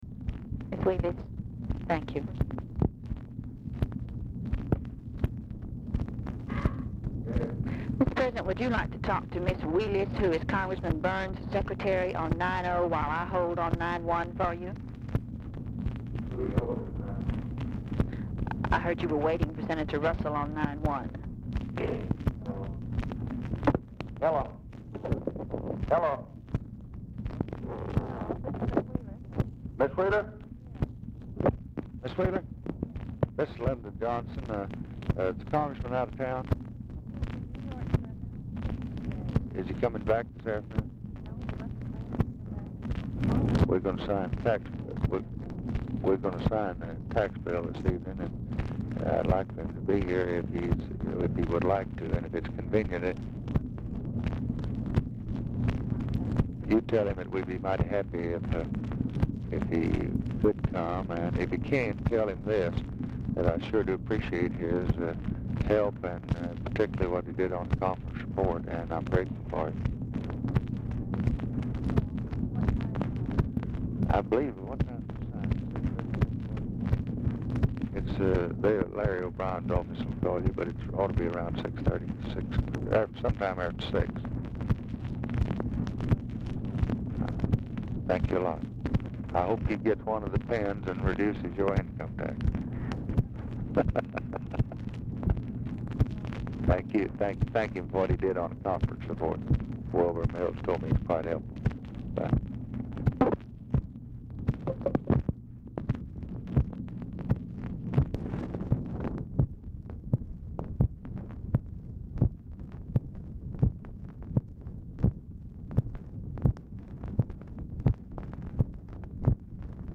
Title Telephone conversation # 2203
Dictation belt
Oval Office or unknown location